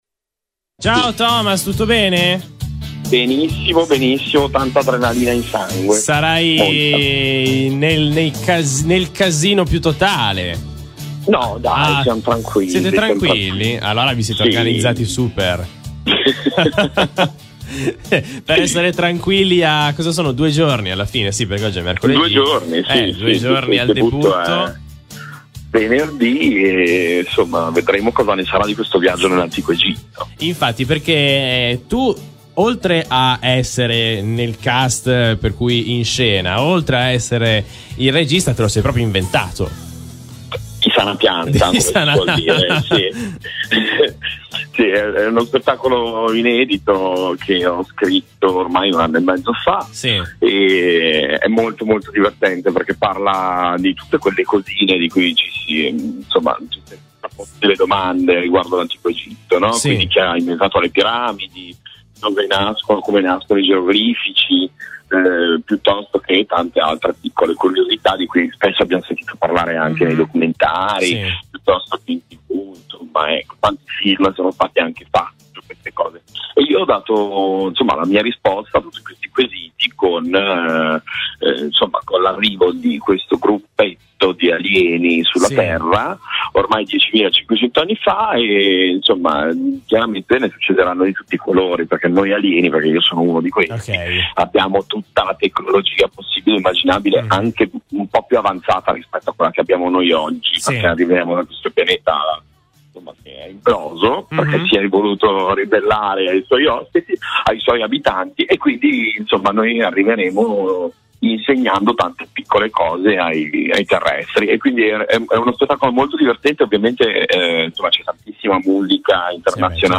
Intervista su Altrostudio Radio per “L’Odissea”